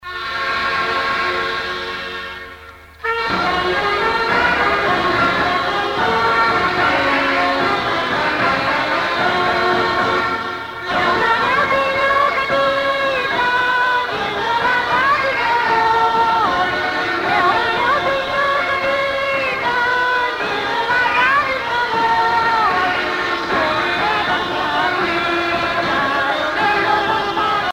danse : vira (Portugal)
Pièce musicale éditée